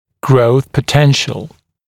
[grəuθ pə’tenʃ(ə)l][гроус пэ’тэнш(э)л]потенциал роста